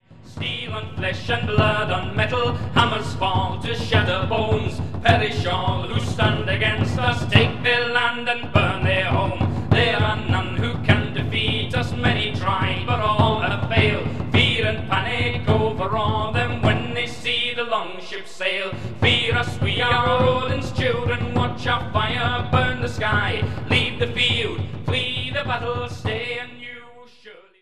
Ibanez PF60CENT1202 'semi' acoustic.
Bodrhan.
Quickshot mic (yes, really!)